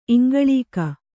♪ iŋgaḷīka